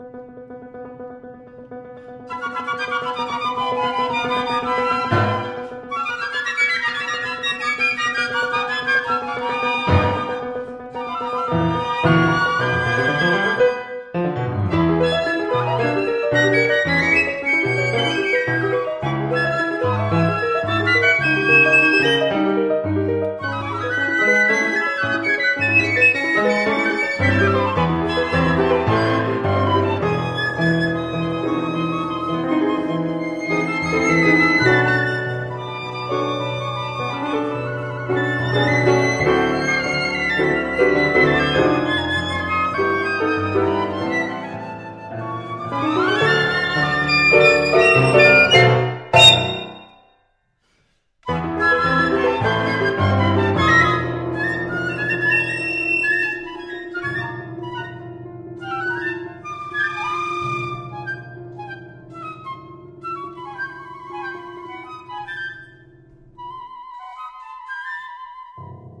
民俗音樂中的和聲元素編織於樂曲中，幾首雙短笛的作品線條交錯，聲響新穎。
有的曲子用短笛摹仿鳥叫，或是打擊樂器等等聲響也憑添不少趣味。
音響上應該注意的是短笛與鋼琴的比例是否有正確呈現，